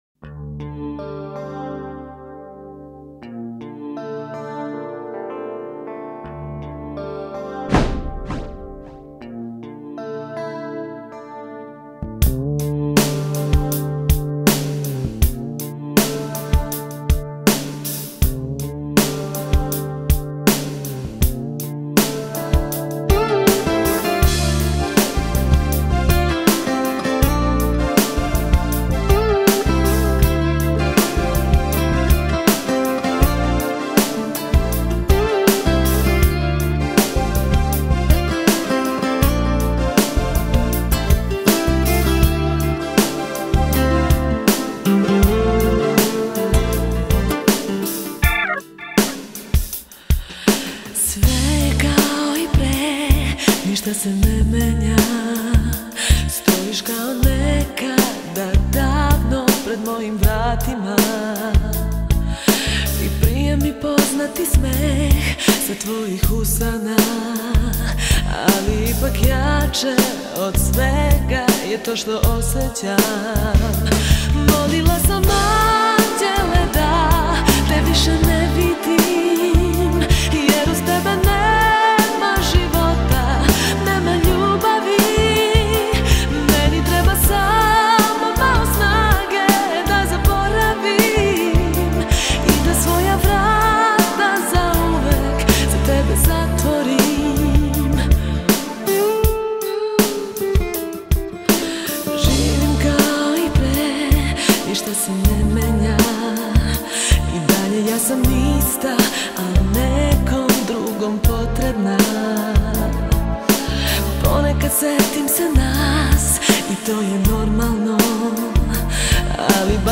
является эмоциональным произведением